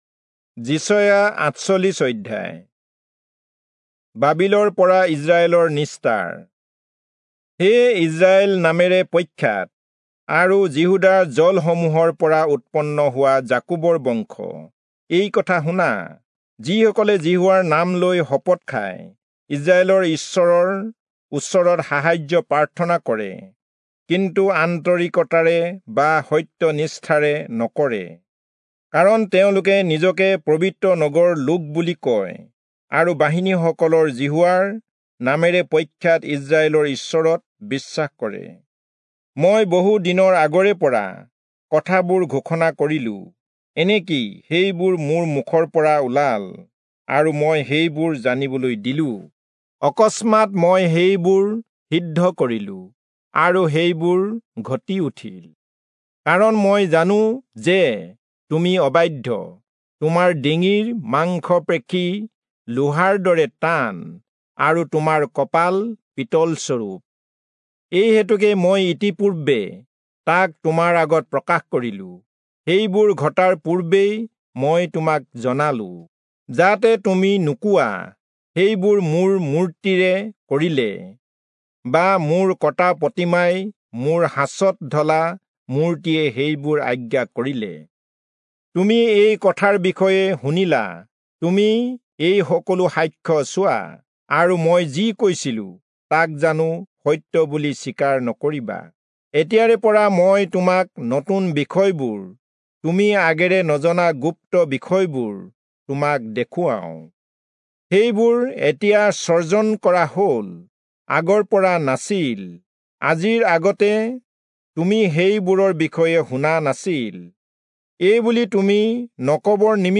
Assamese Audio Bible - Isaiah 16 in Irvmr bible version